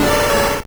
Cri de Flagadoss dans Pokémon Or et Argent.